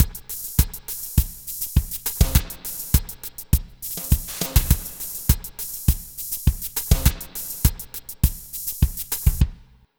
Ala Brzl 1 Drmz.wav